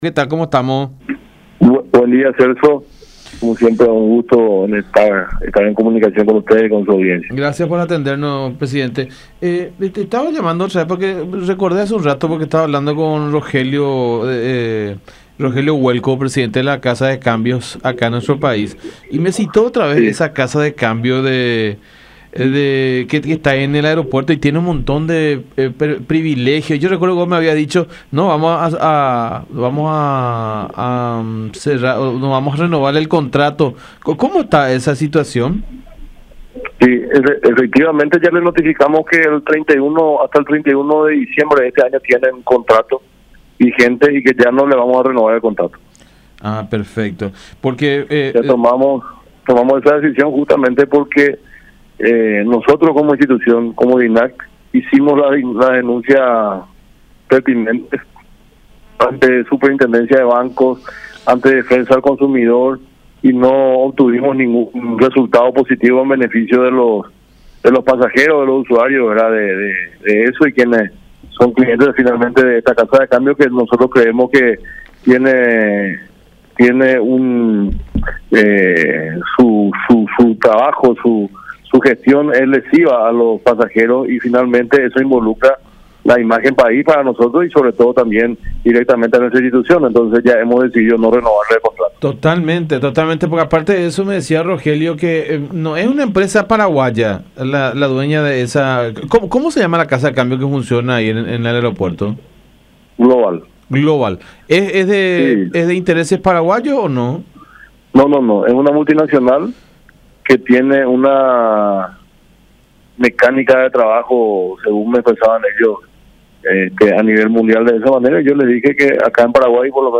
“Su gestión es dañina para los pasajeros y afecta la imagen país que tenemos actualmente” Nosotros nos preocupamos y por sobre todo, nos ocupamos de las denuncias hechas por los pasajeros”, explicó Édgar Melgarejo, presidente de la Dirección Nacional de Aeronáutica Civil (DINAC), en comunicación con La Unión.